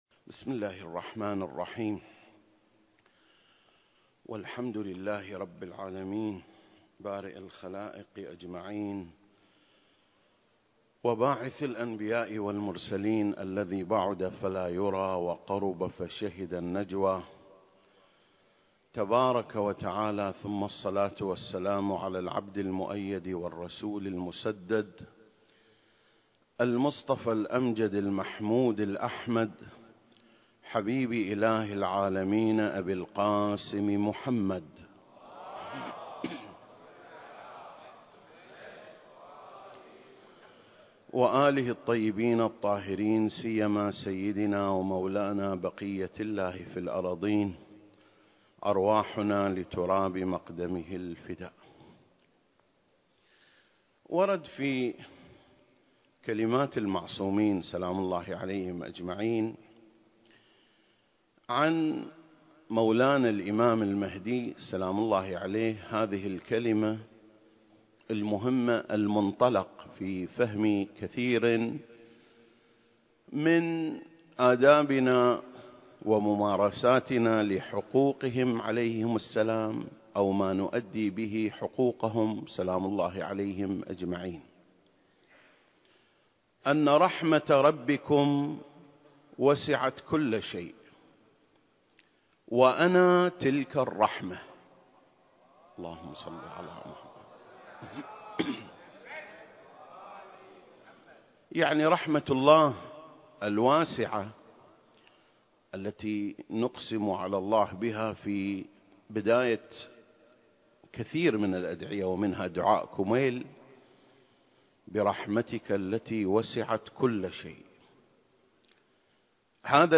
عنوان الخطبة: المهدي (عجّل الله فرجه) رحمة الله الواسعة (1) المكان: مسجد مقامس/ الكويت التاريخ: 2024